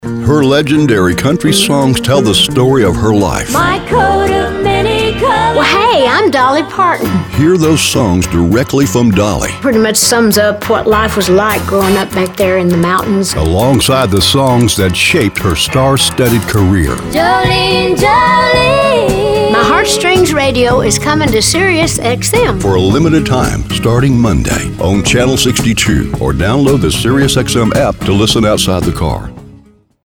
Slight southern, Texas accent
Middle Aged